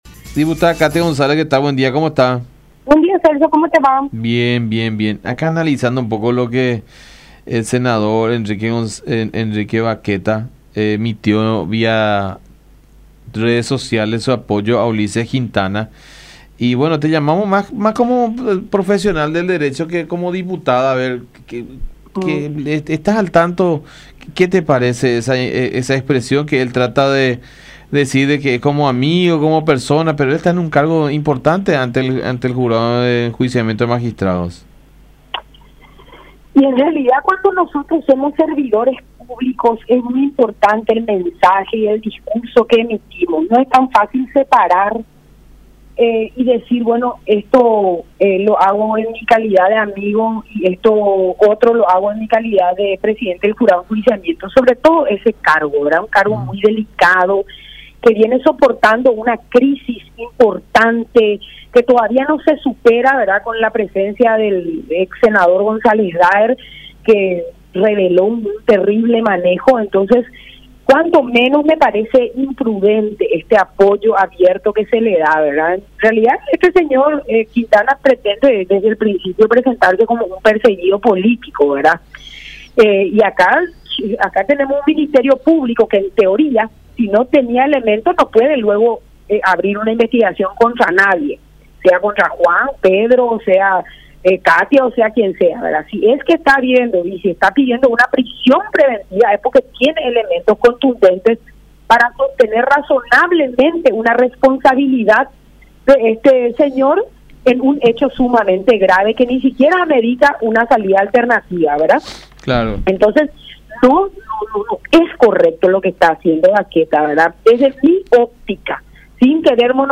“Es un mensaje muy grave, se puede tomar como una presión”, expresó la legisladora también en diálogo con La Unión.